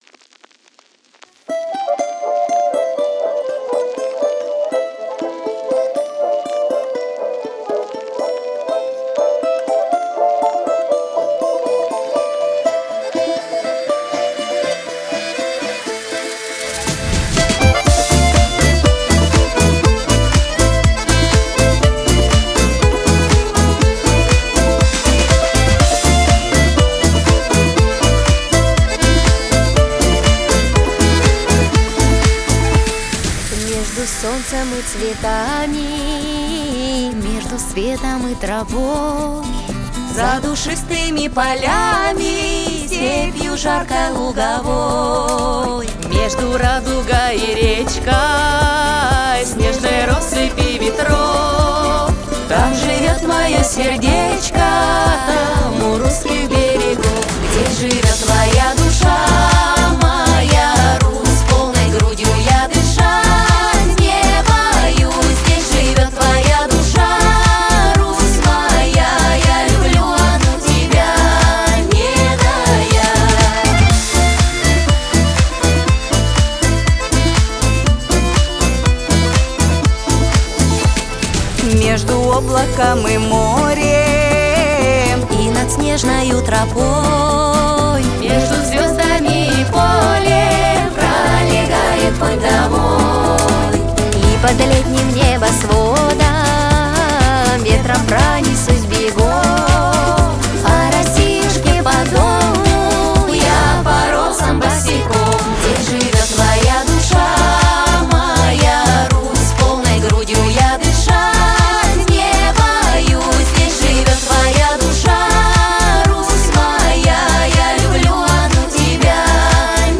• Жанр: Детские песни
народный мотив